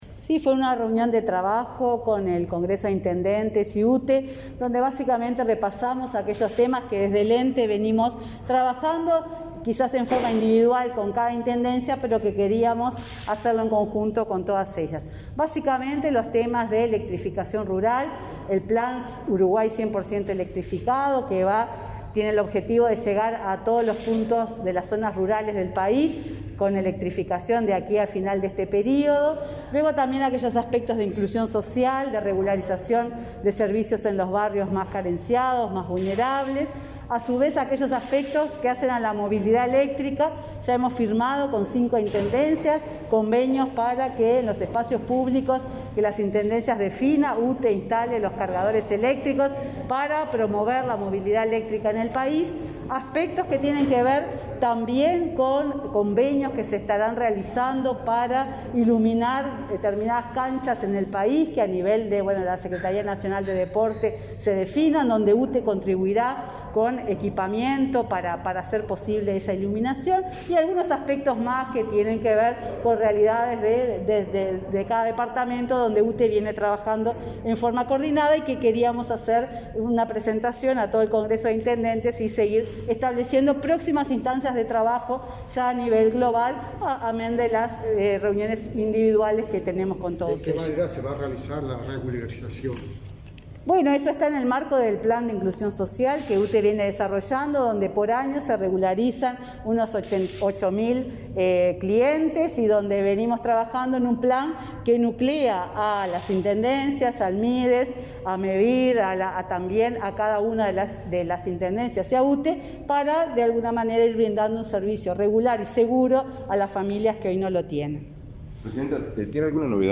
Declaraciones de la presidenta de UTE, Silvia Emaldi, a la prensa